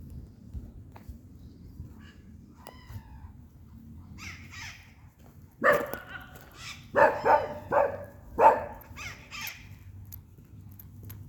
Blue-crowned Parakeet (Thectocercus acuticaudatus)
Dos ejemplares alimentándose en un árbol de Lapacho
Location or protected area: San Miguel, capital
Condition: Wild
Certainty: Recorded vocal